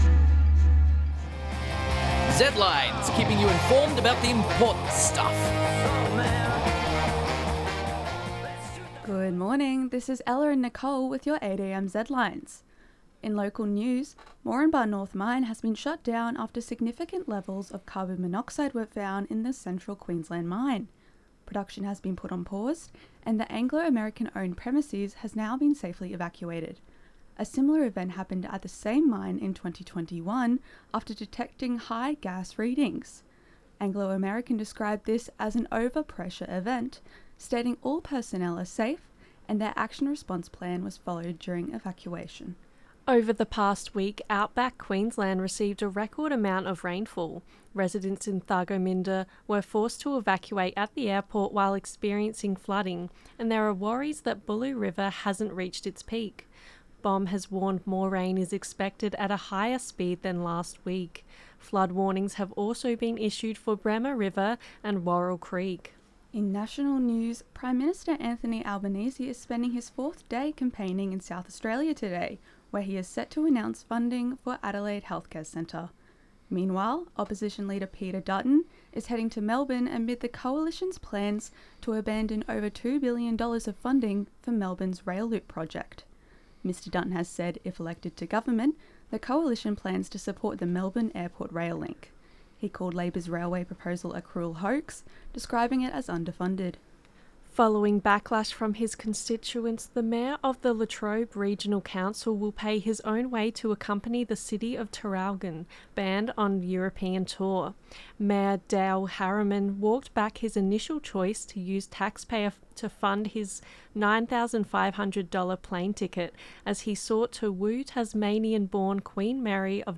Marine Le Pen, atizando el odio (Conecta Abogados/flickr under CC BY-ND 2.0) Zedlines Bulletin 8AM ZEDLINES 1.4.25.mp3 (7.54 MB)